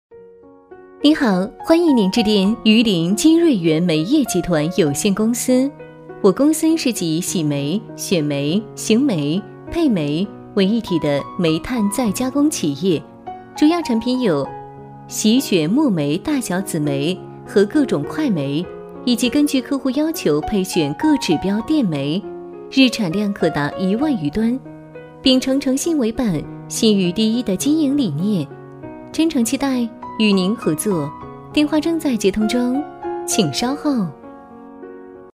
女3号
榆林金瑞源煤业集团有限公司(彩铃)